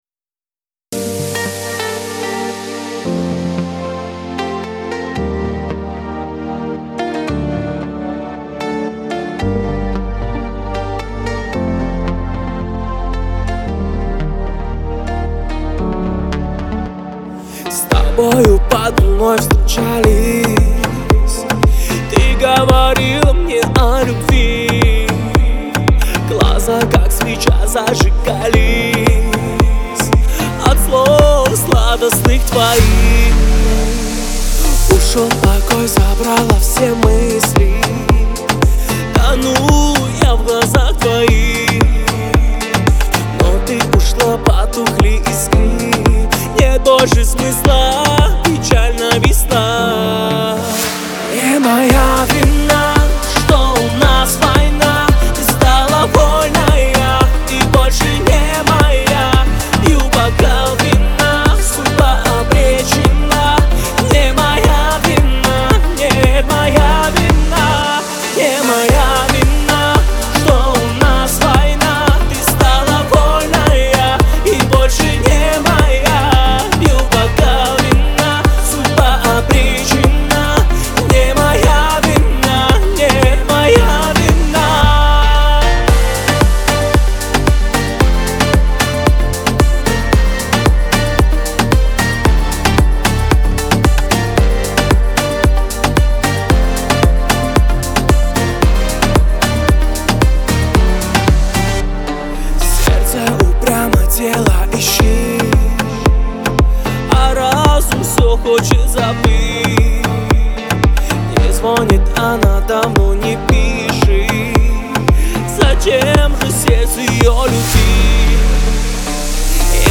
Кавказ – поп , грусть